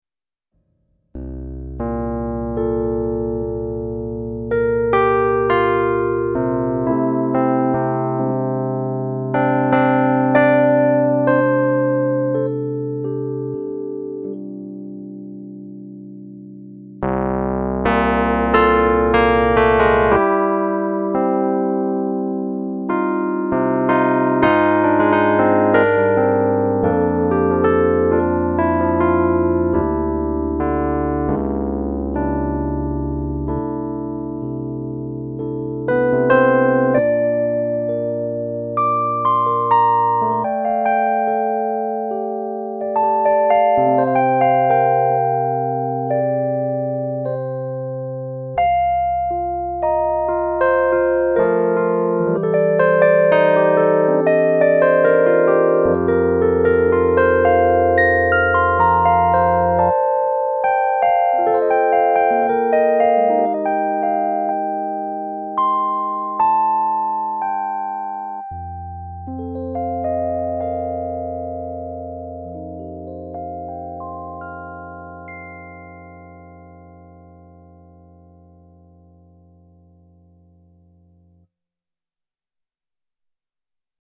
Everything was played right on the AstroLab, and there was no extra sound processing done. We simply recorded straight into a DAW and exported the audio as-is.
Let’s kick things off with a classic electric piano preset, the default Clean Mark V.